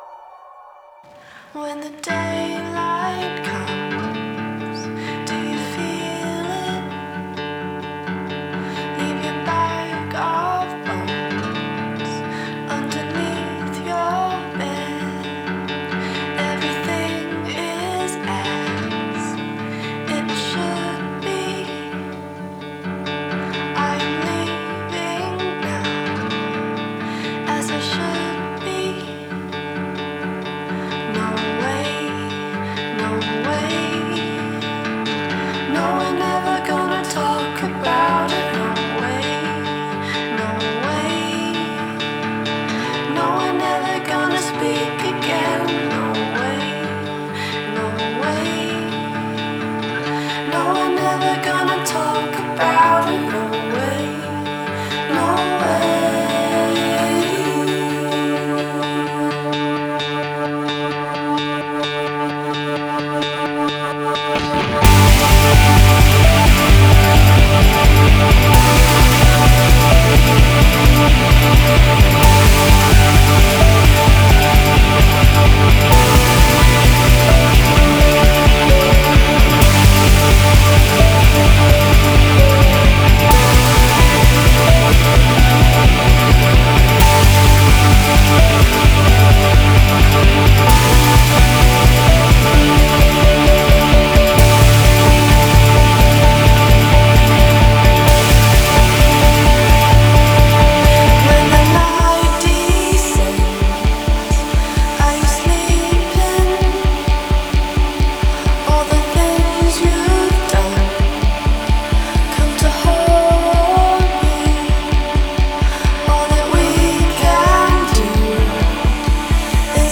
indie rock quintet
a song with a certain finality that felt stirring live.
swaying as she sang and played the keyboard.